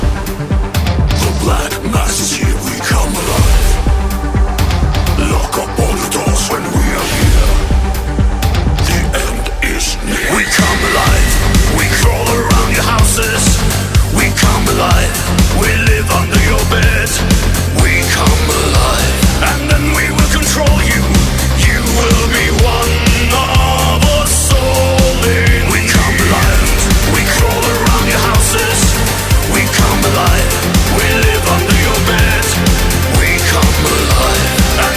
# Метал